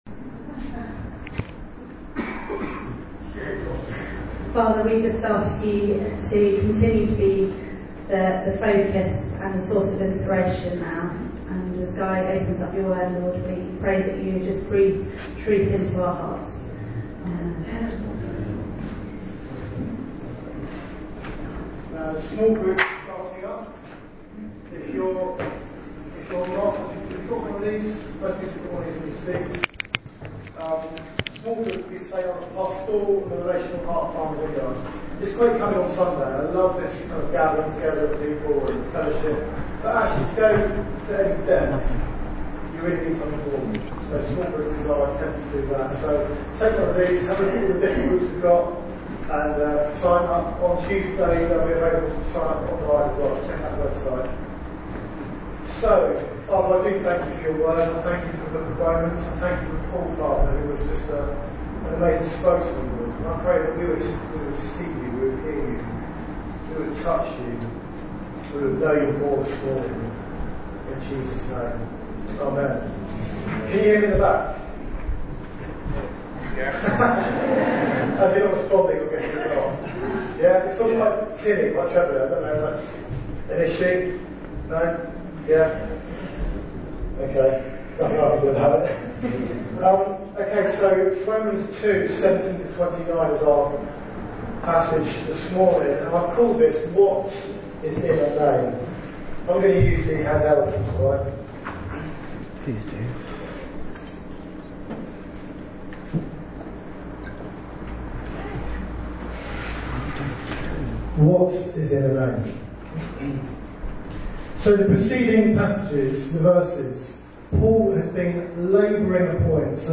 Service Type: Sunday Meeting Bible Text: Romans 2:17-29 What’s in a name?